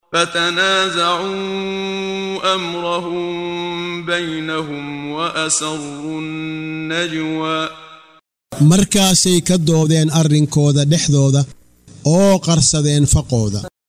Waa Akhrin Codeed Af Soomaali ah ee Macaanida Suuradda Ta Ha oo u kala Qaybsan Aayado ahaan ayna la Socoto Akhrinta Qaariga Sheekh Muxammad Siddiiq Al-Manshaawi.